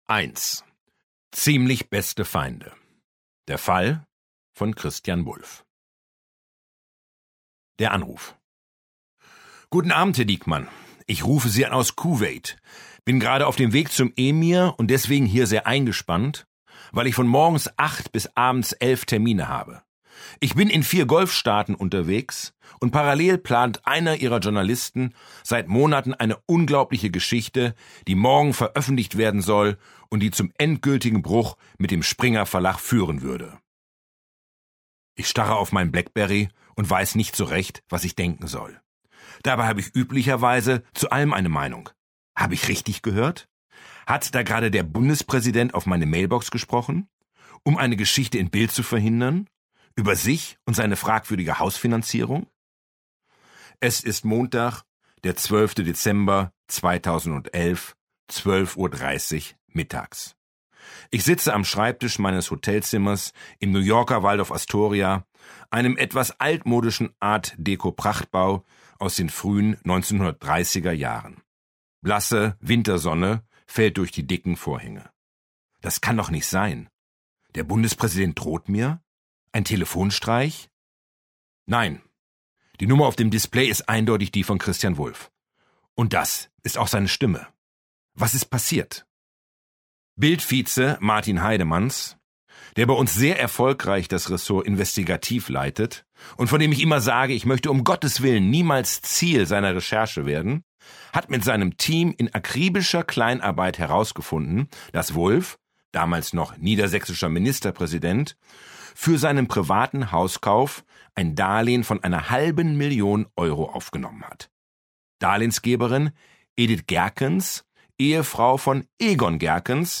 Kai Diekmann (Sprecher)